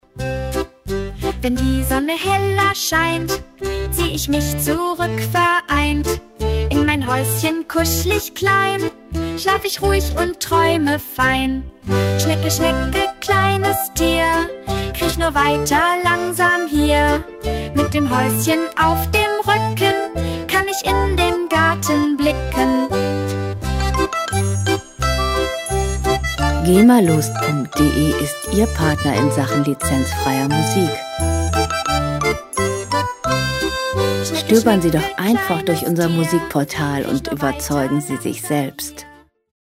Gema-freie Kinderlieder
Musikstil: Kinderlied
Tempo: 87 bpm
Tonart: C-Dur
Charakter: niedlich, süß
Instrumentierung: Akkordeon, Xylophon, Kindergesang